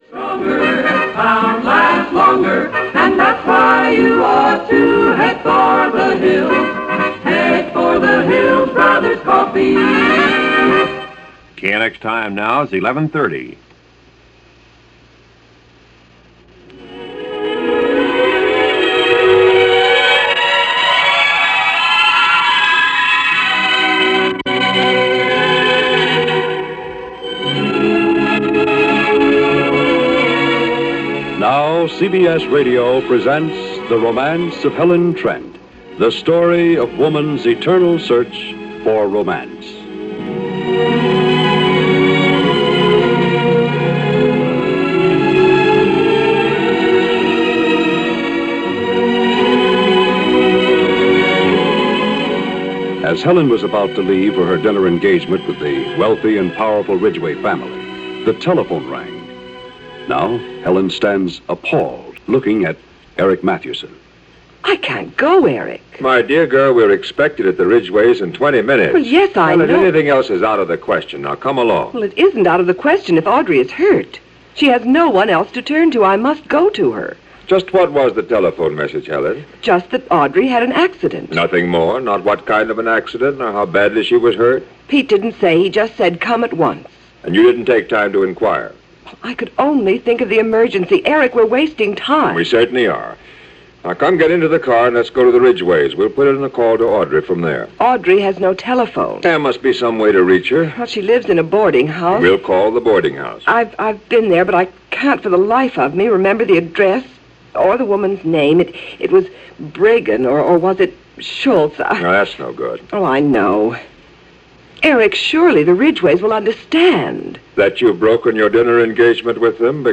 Helen Trent vs The Beat Generation - 1959 - Daytime Radio soap opera dealing with the Beat Generation and youth out of control.